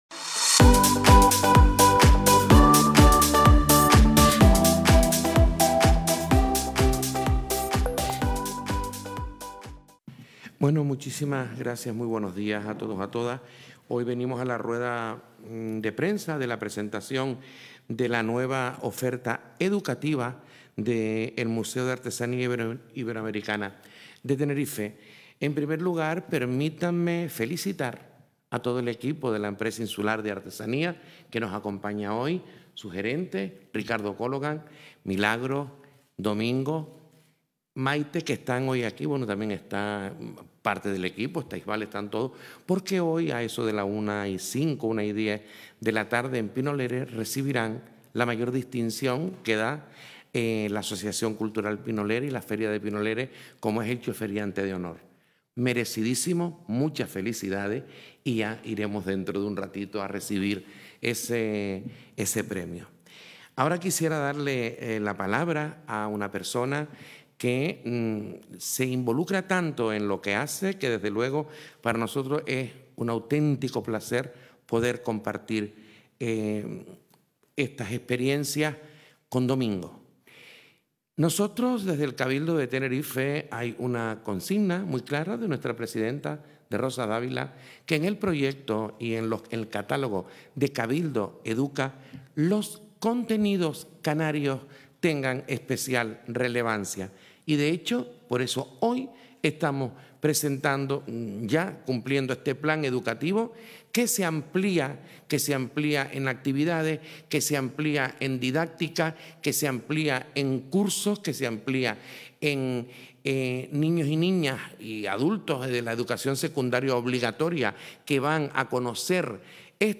El Cabildo de Tenerife ha presentado la nueva oferta educativa del Museo de Artesanía Iberoamericana de Tenerife (MAIT) con vistas al curso 2024/2025, en una rueda de prensa a la que han asistido el consejero de Educación y Empleo, Efraín Medina;...